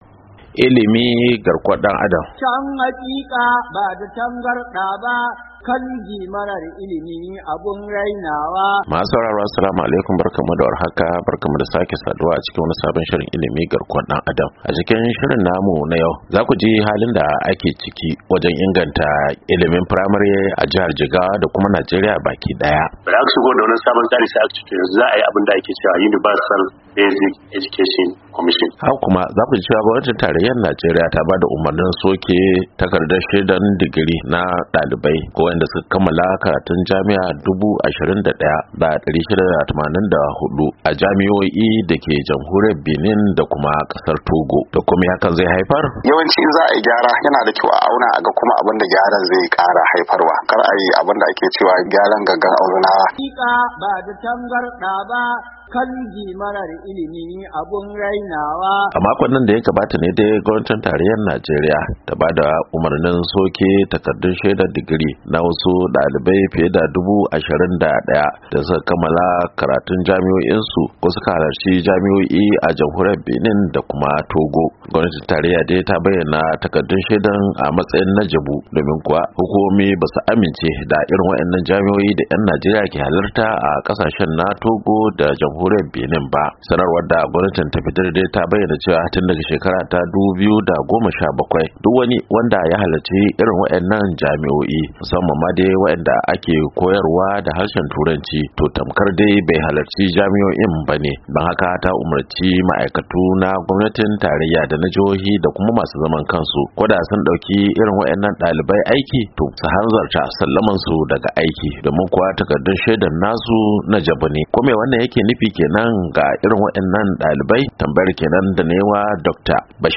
Mun kuma tattaunawa da masana ilimi da masu sharhi da dalibai kan wannan batu, haka kuma mun yi hira da kwamishanan ilimi na jigawa kan ilimin firamare.